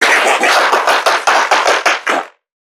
NPC_Creatures_Vocalisations_Infected [48].wav